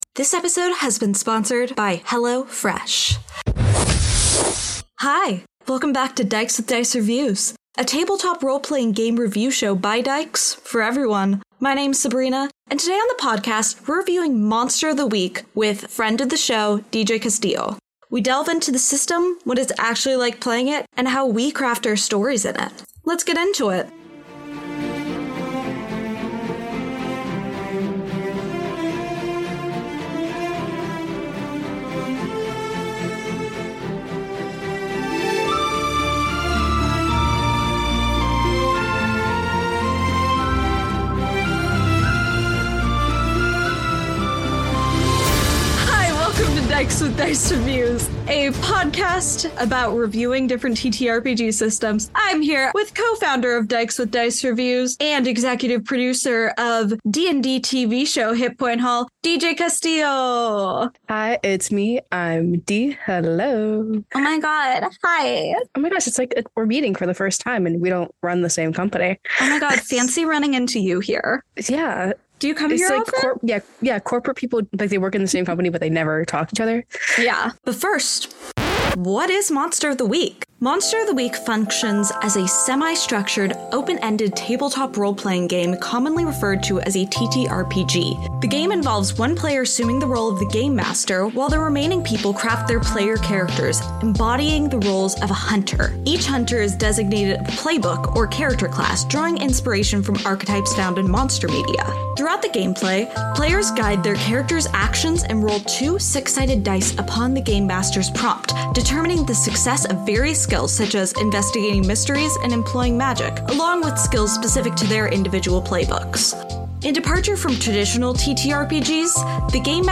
Rating : PG for mild swearing and references to violence.